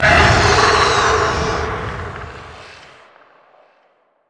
c_alien_bat2.wav